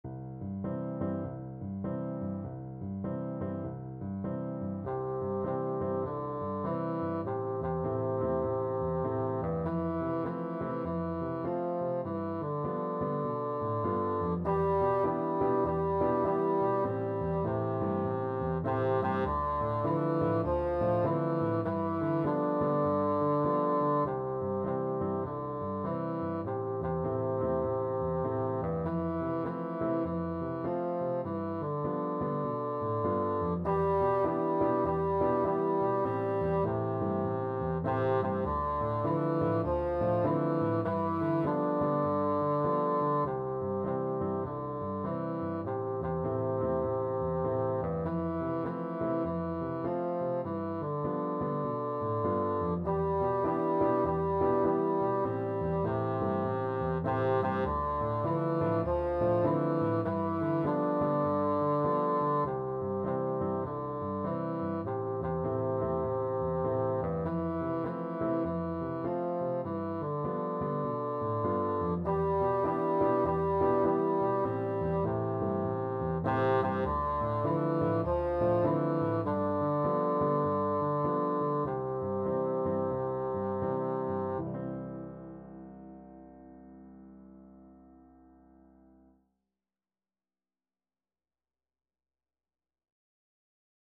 Bassoon
Traditional Scottish ballad
C major (Sounding Pitch) (View more C major Music for Bassoon )
With swing =c.100